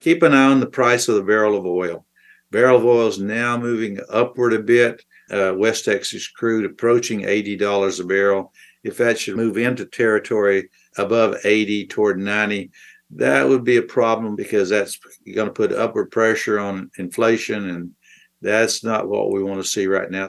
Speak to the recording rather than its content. during a video briefing on Friday